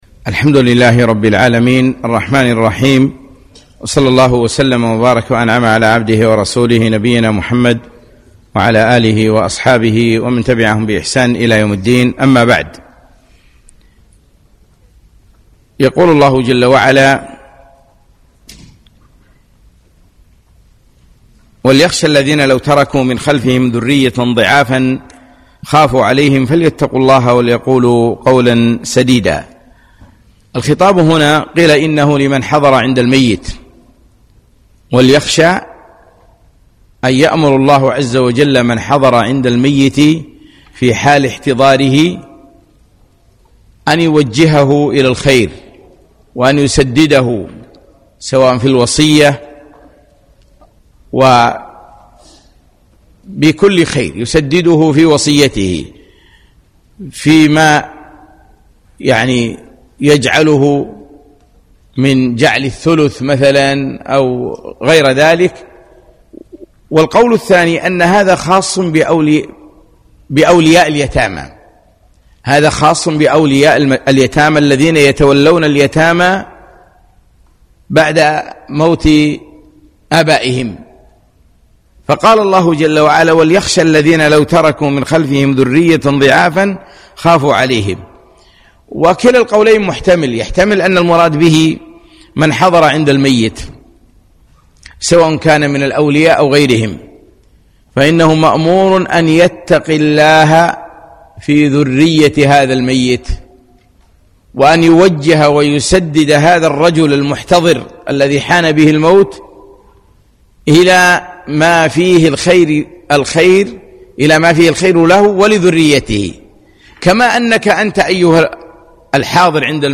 يوم الجمعة 9 3 2018 مسجد صالح الكندري بعد صلاة المغرب